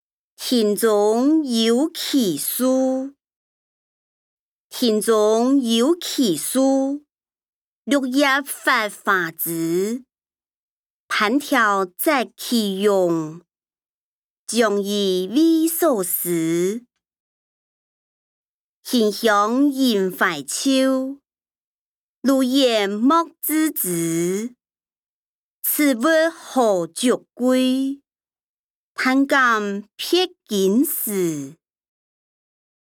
古典詩-庭中有奇樹音檔(四縣腔)